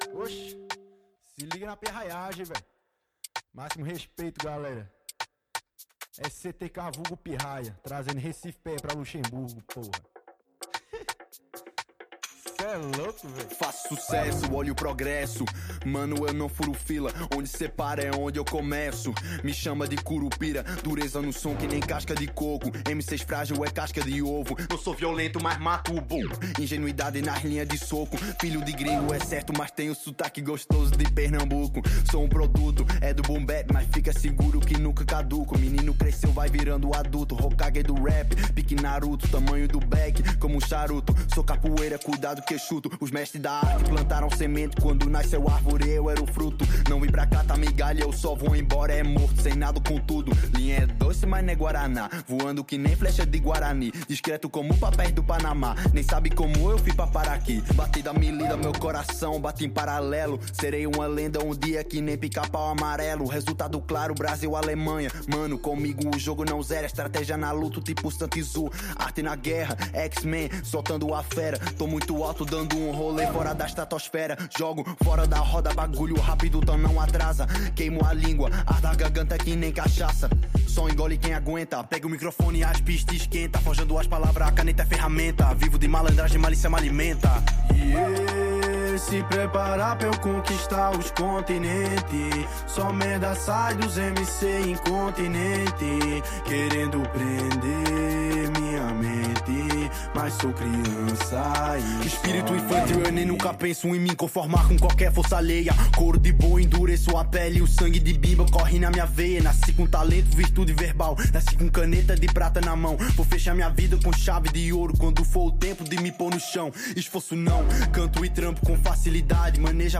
Bistro – 22.05.2024 – Interview Francofolies Festival 2024